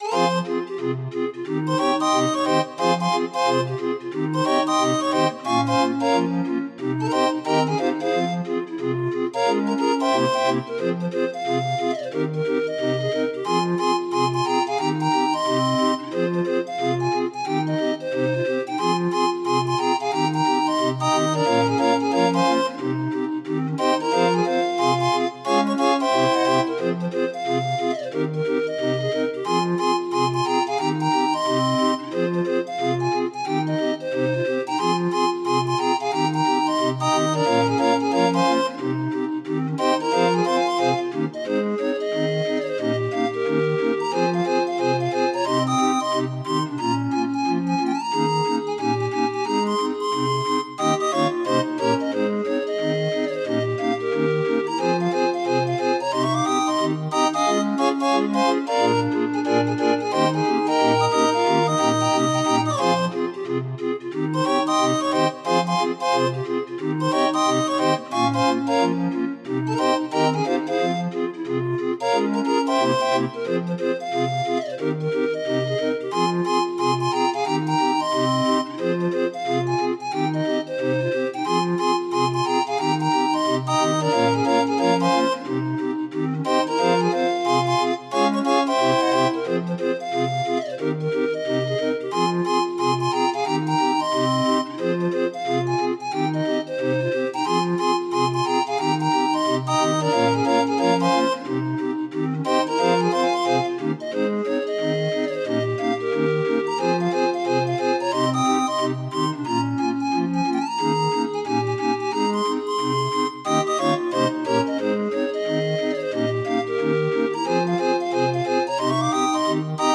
Demo of 31 note MIDI file